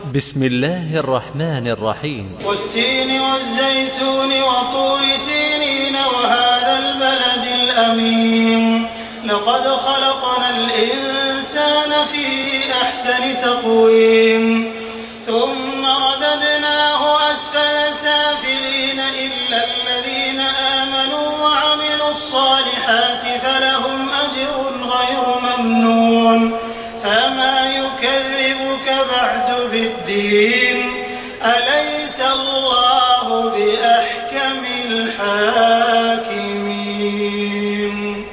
Surah আত-তীন MP3 by Makkah Taraweeh 1428 in Hafs An Asim narration.
Murattal